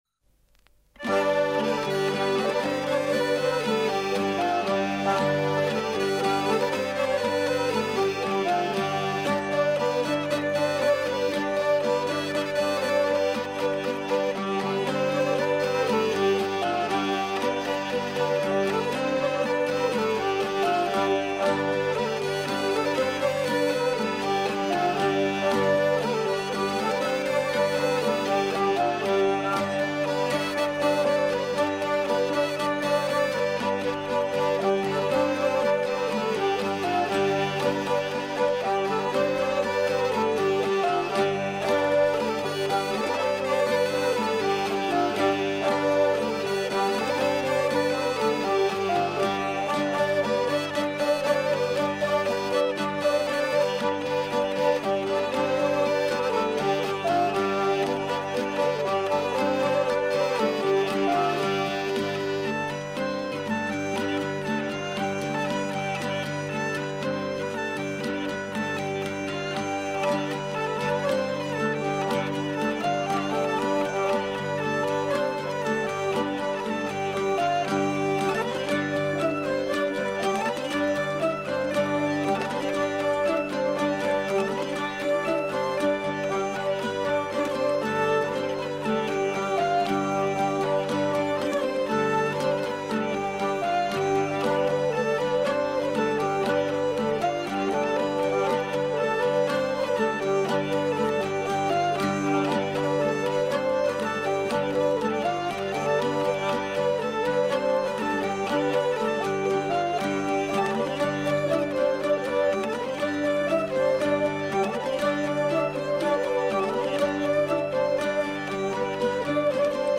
branle double (4 temps) : 2 pas à gauche, 2 pas à droite
branle double.mp3